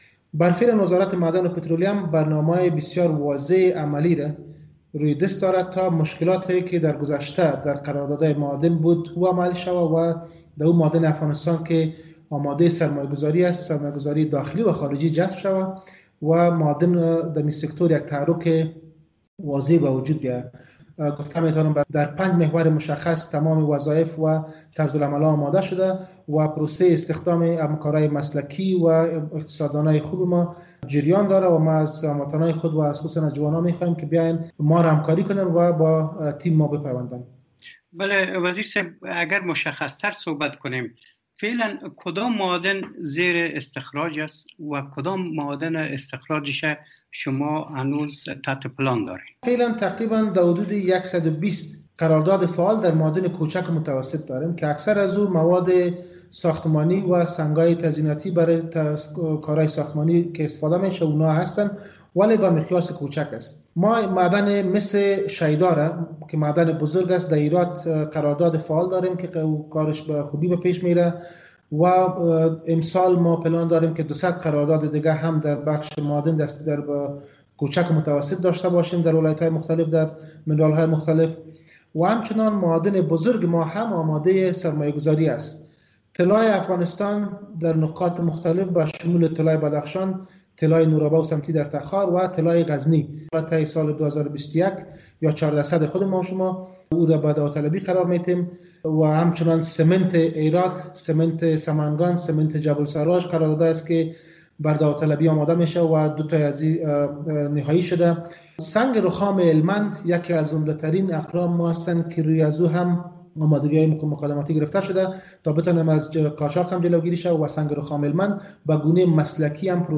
گفتگوی ویژه با وزیر معادن و پترولیم افغانستان